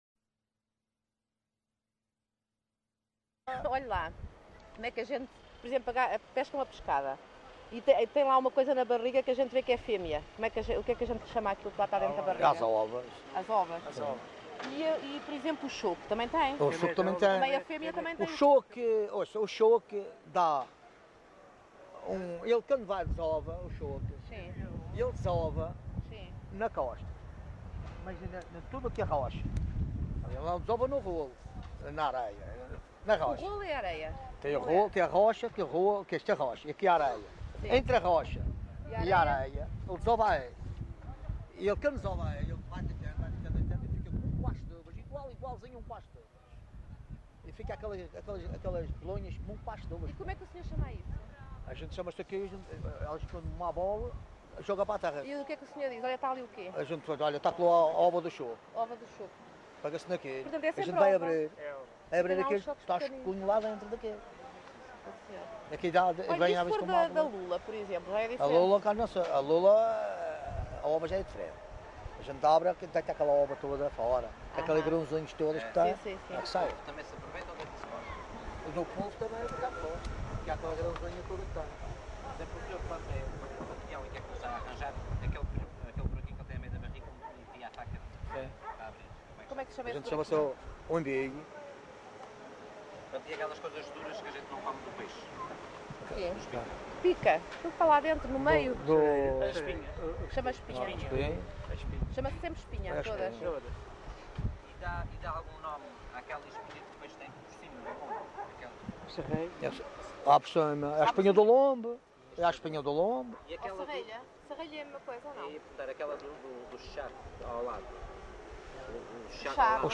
LocalidadeCâmara de Lobos (Câmara de Lobos, Funchal)